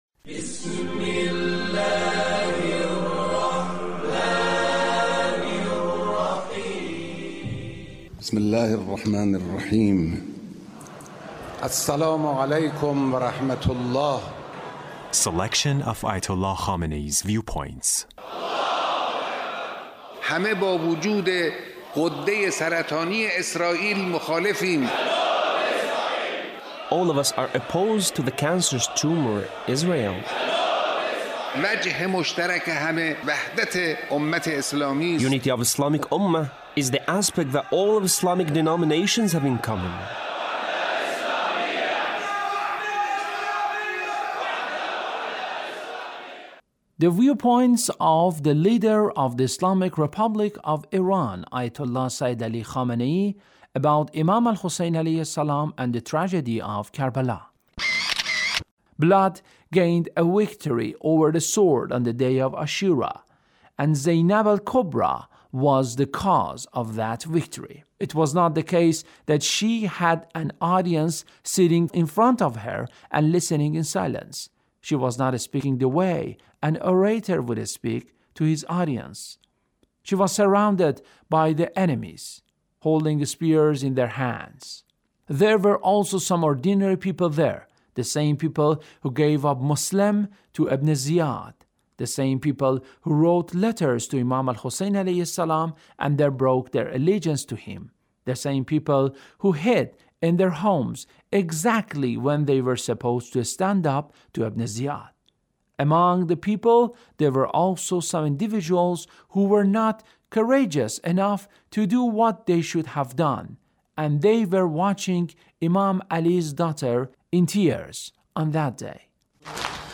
Leader's Speech (1779)